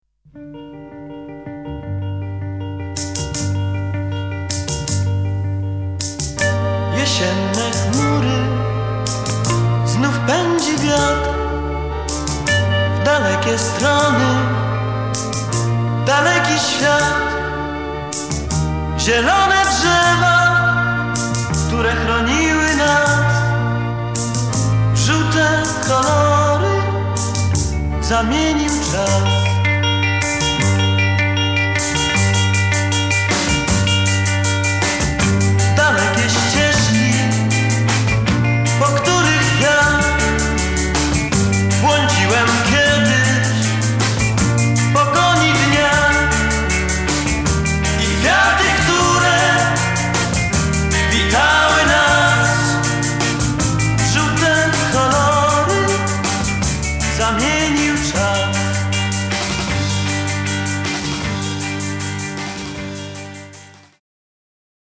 DUET
VOC GUITAR KEYB BASS DRUMS TEKST